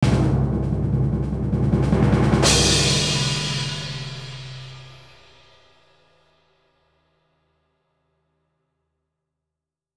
drum-roll-please-24b.wav